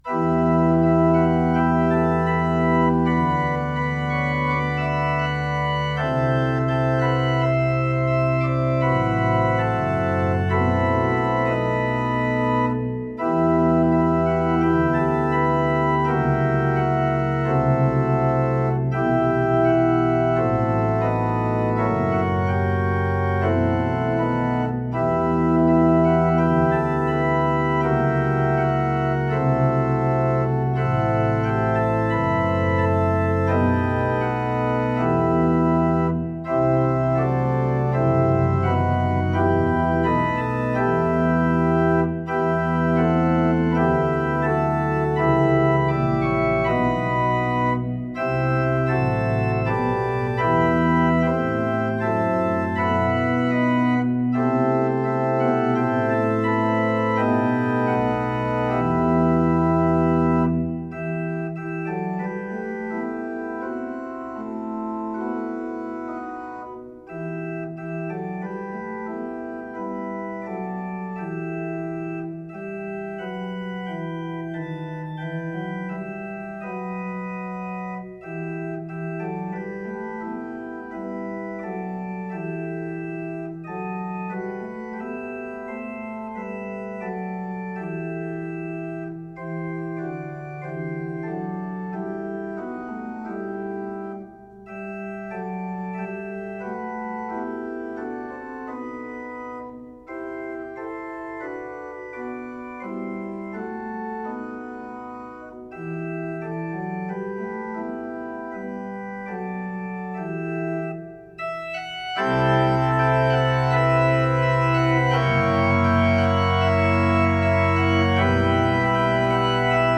Postlude on “Aberystwyth” – The Organ Is Praise
My setting of Aberystwyth is intended as a grand postlude.